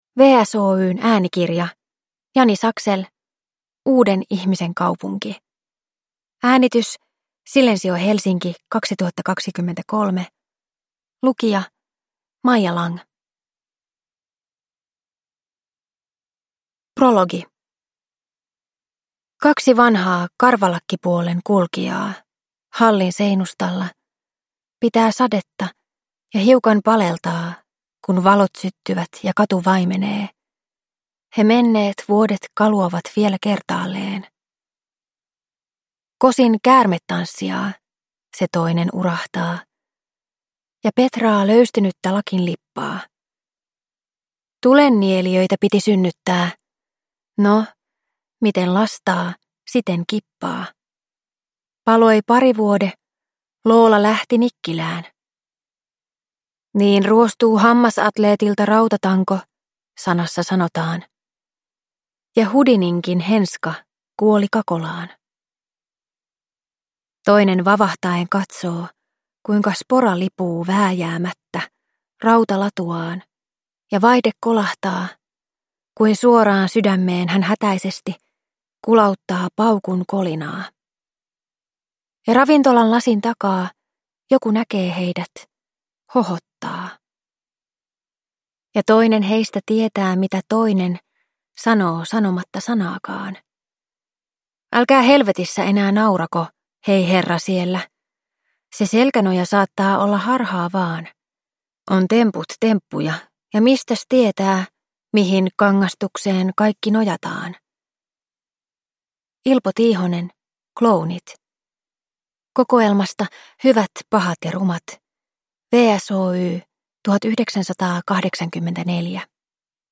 Uuden ihmisen kaupunki – Ljudbok – Laddas ner